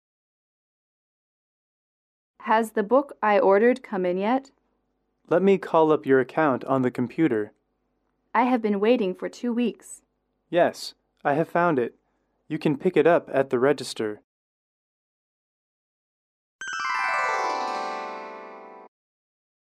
英语主题情景短对话24-2：订书取货(MP3)
英语口语情景短对话24-2：订书取货(MP3)